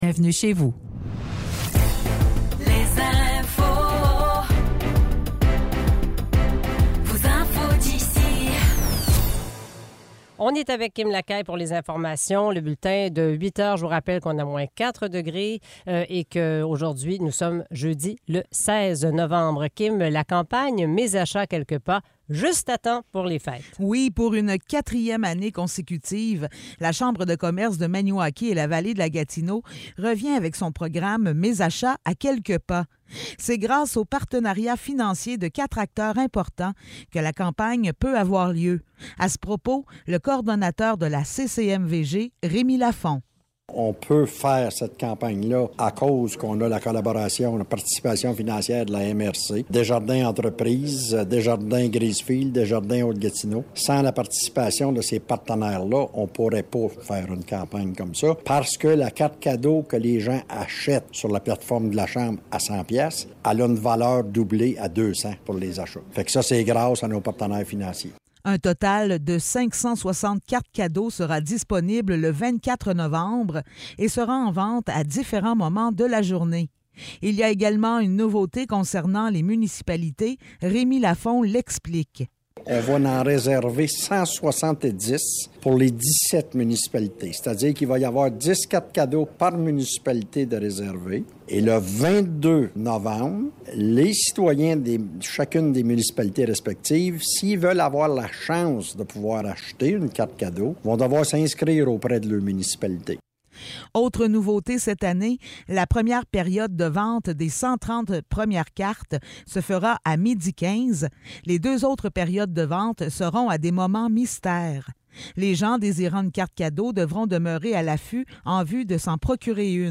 Nouvelles locales - 16 novembre 2023 - 8 h